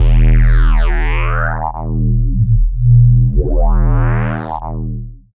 Mad Filta Vox Fx.wav